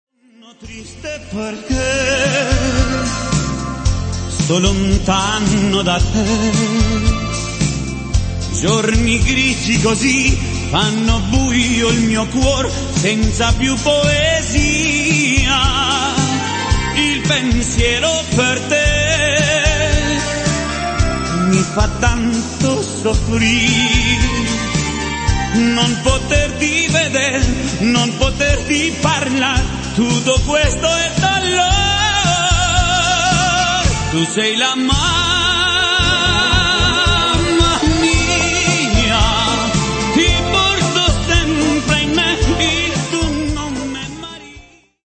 beguine